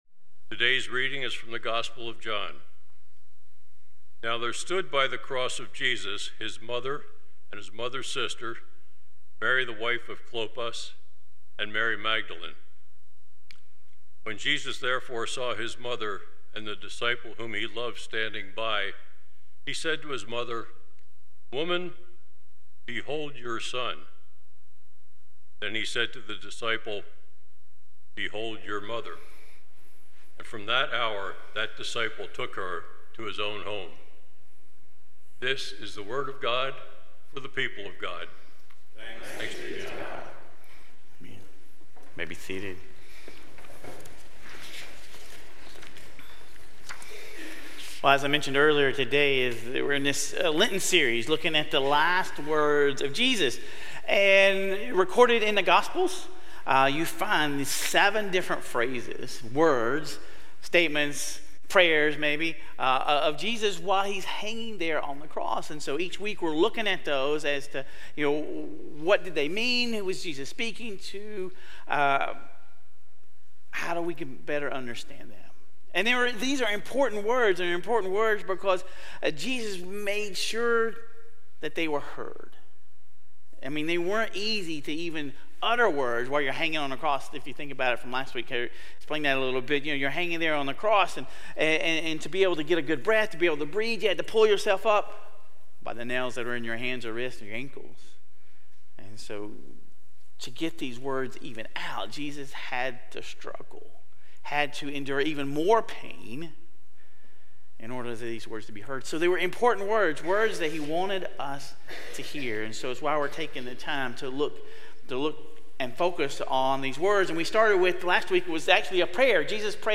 Sermon Reflections: In what ways does Mary's faithful presence at both the beginning and end of Jesus' life speak to you about perseverance in faith through difficult circumstances?